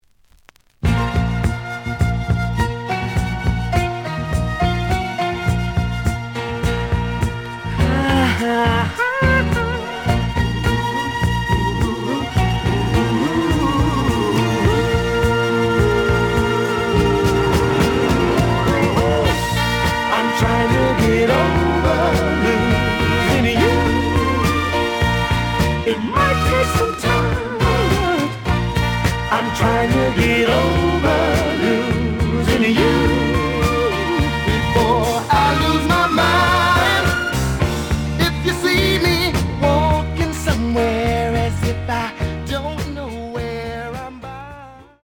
試聴は実際のレコードから録音しています。
●Genre: Soul, 70's Soul
●Record Grading: VG (両面のラベルに若干のダメージ。盤に若干の歪み。傷は多いが、プレイはまずまず。)